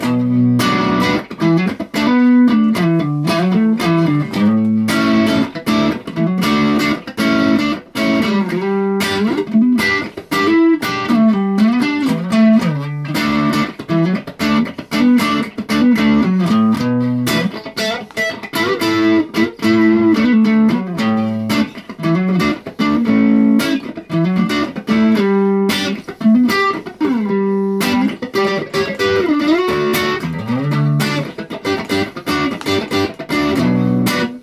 demoing this style using sus chord additions and fills